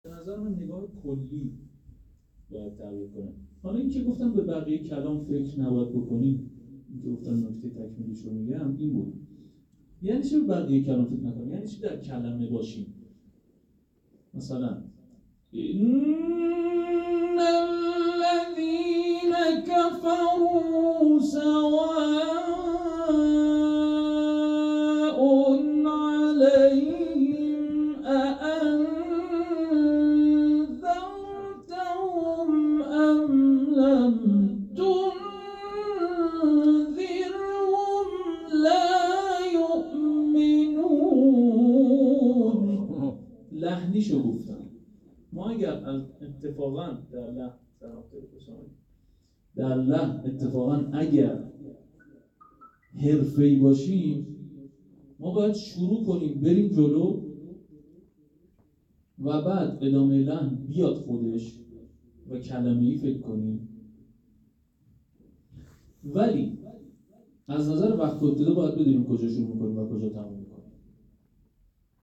این جلسه قرآن به همت بخش قرآنی دفتر نماینده ولی‌فقیه در استان البرز با آیین افتتاحیه‌ای اجمالی همراه بود.
جلسه آموزش عمومی قرآن